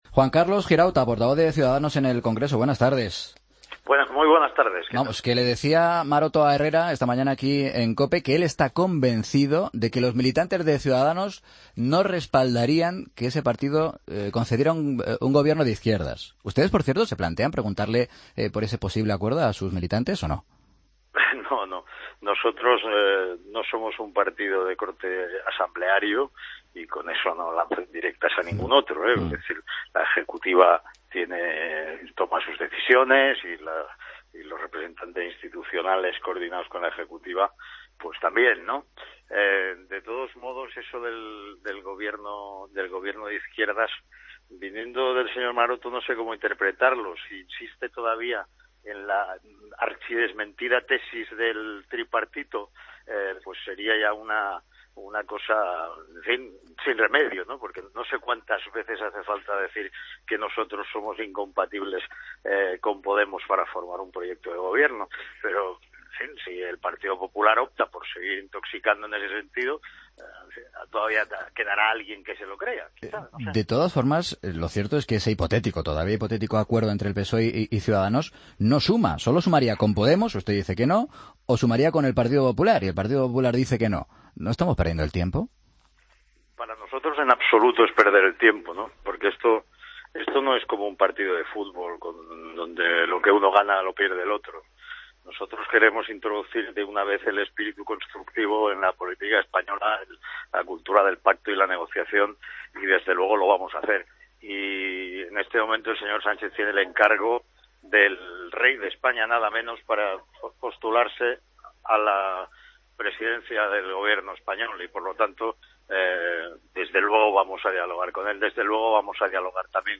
Juan Carlos Girauta, portavoz de Ciudadanos en el Congreso en Mediodía COPE